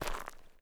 mining sounds